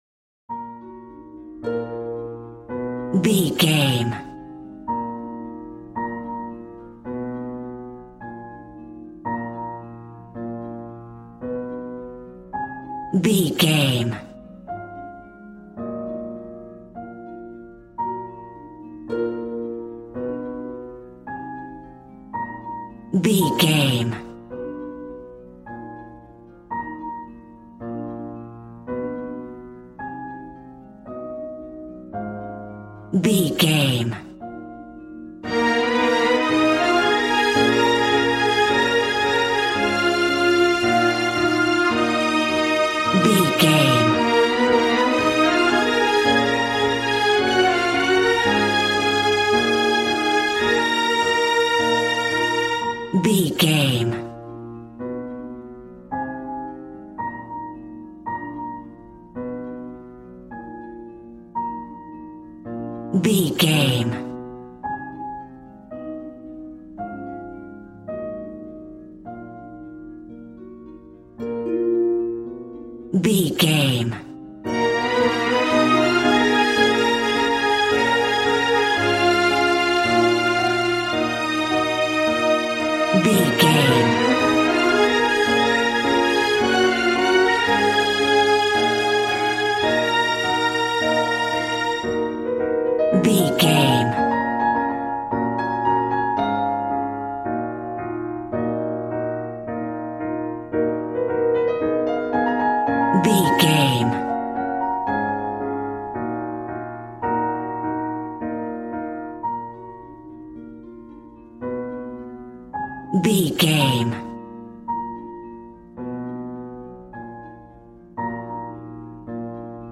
Regal and romantic, a classy piece of classical music.
Aeolian/Minor
B♭
regal
strings
violin